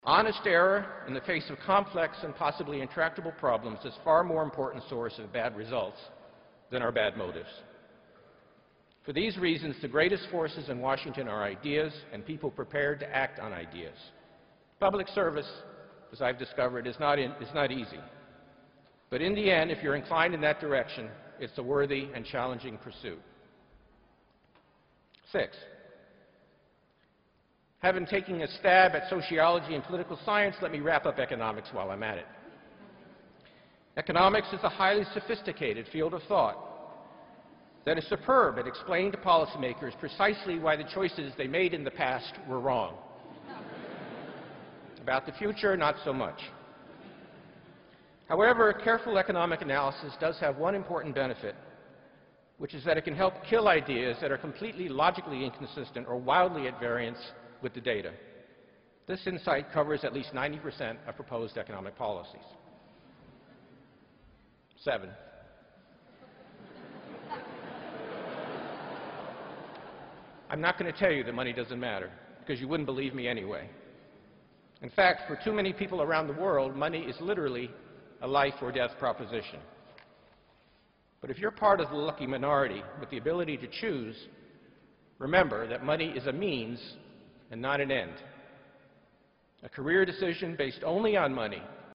公众人物毕业演讲第309期:本伯南克2013普林斯顿(8) 听力文件下载—在线英语听力室